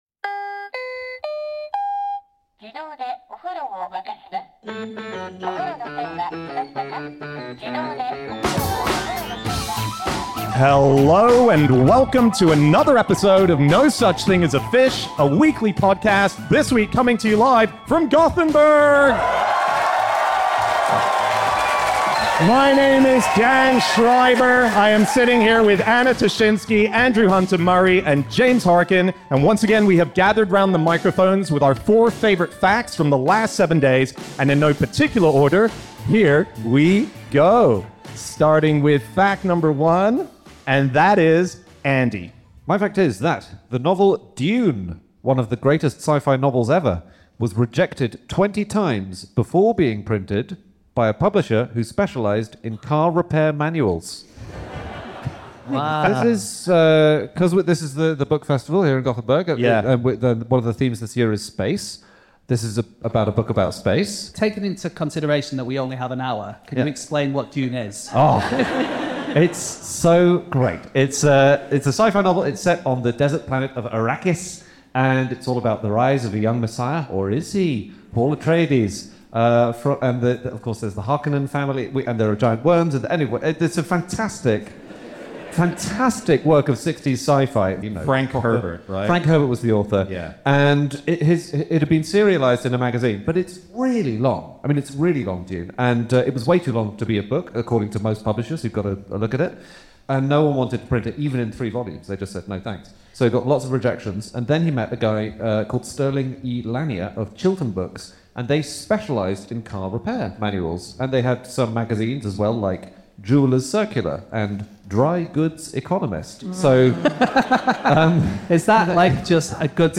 Live from the Gothenburg Book Festival